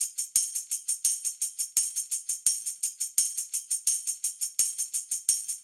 Index of /musicradar/sampled-funk-soul-samples/85bpm/Beats
SSF_TambProc1_85-02.wav